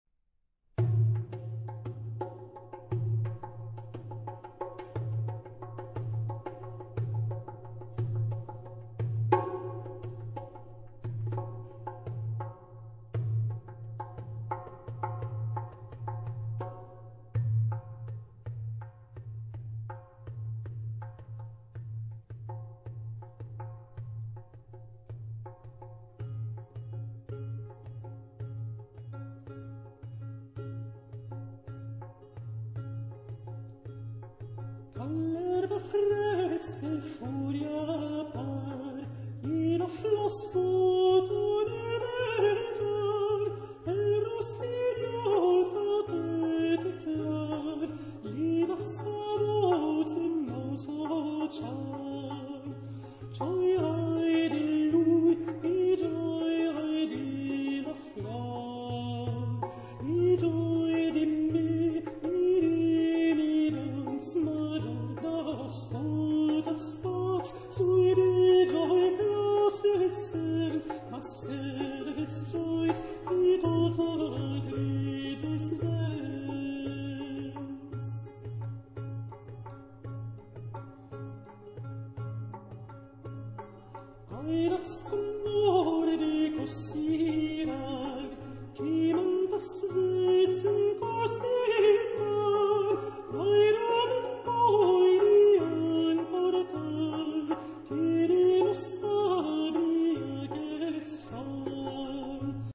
都是12世紀法國Occitane地區遊唱詩人作品，
風格素雅、簡單、但情感深邃。
簡單的古樂伴奏，加上單一男聲、女聲，在12世紀那個黑暗的時代裡，